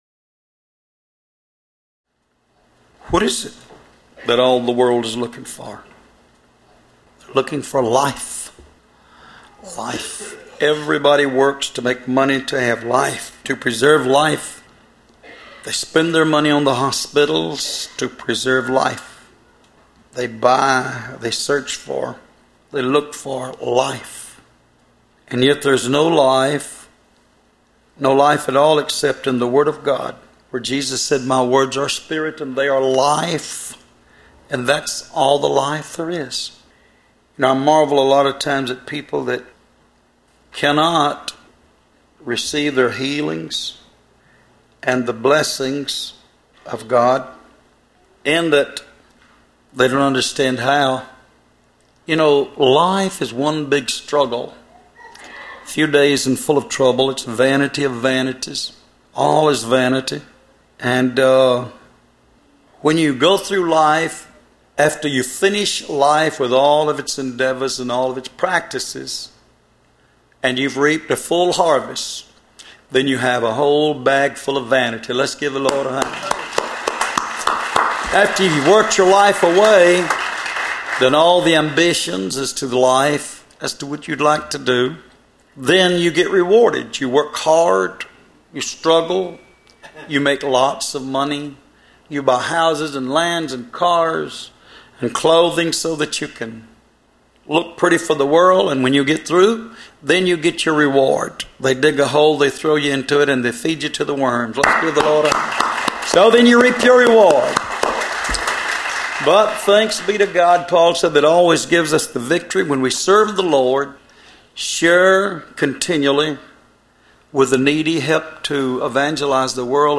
Location: Love’s Temple in Monroe, GA USA
Sermons